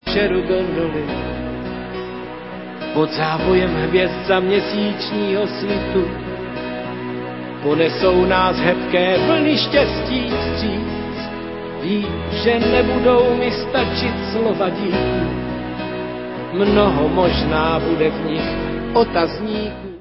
české pop-music